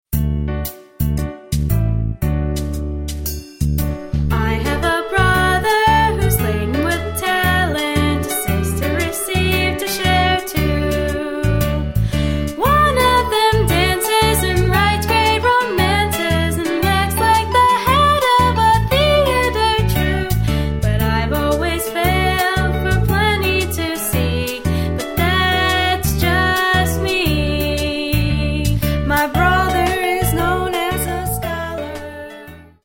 Sample from the Vocal CD